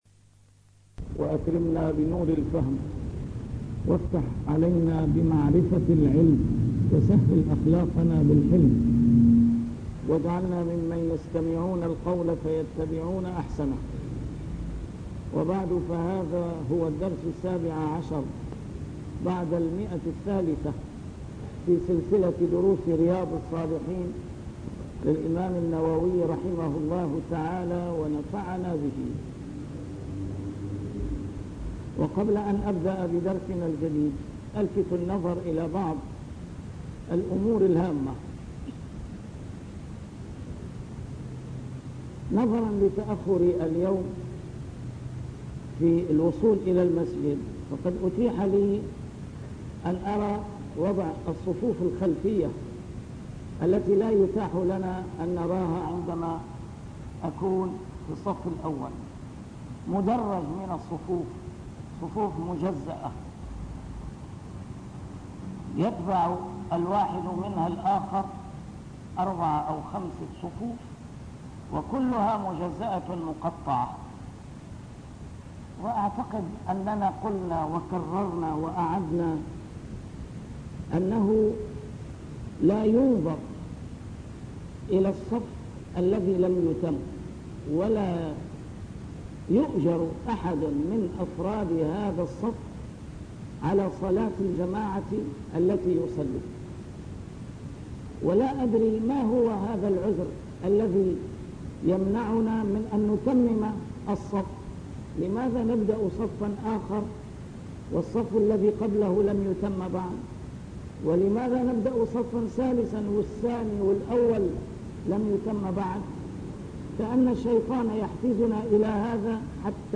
A MARTYR SCHOLAR: IMAM MUHAMMAD SAEED RAMADAN AL-BOUTI - الدروس العلمية - شرح كتاب رياض الصالحين - 317- شرح رياض الصالحين: تحريم الظلم